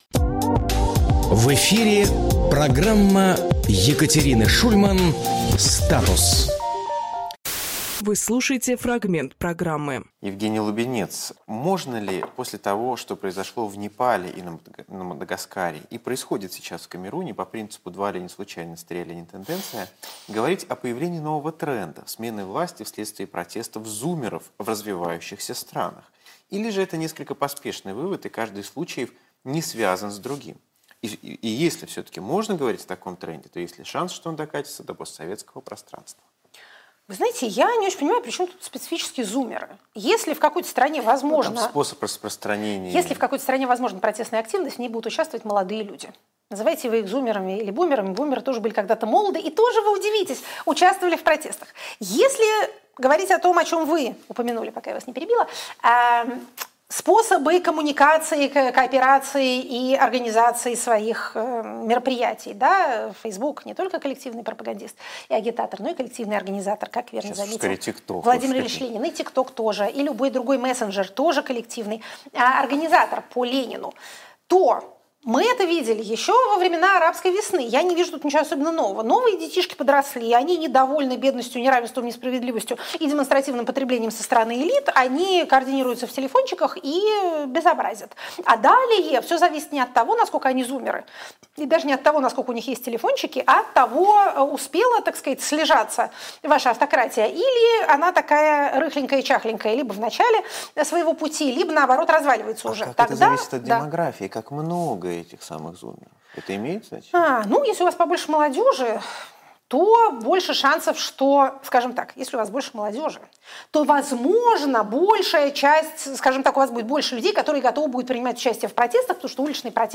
Фрагмент эфира от 28.10.25